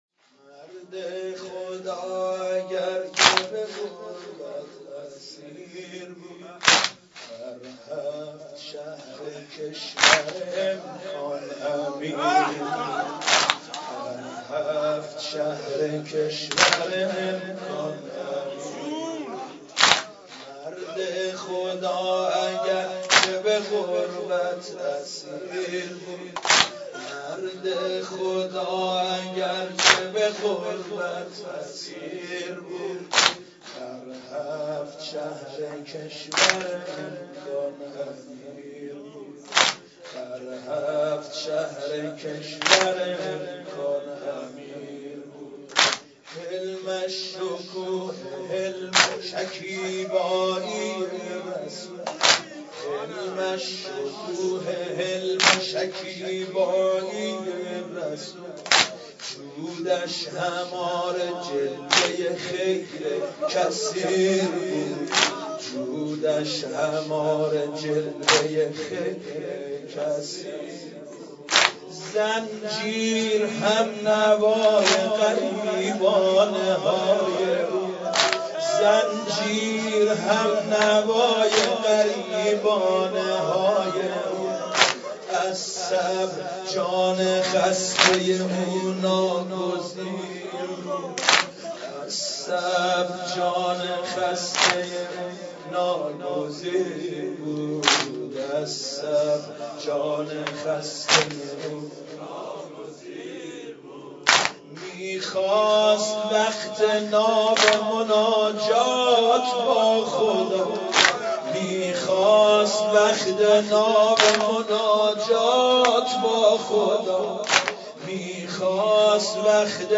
مداحی شهادت امام موسی کاظم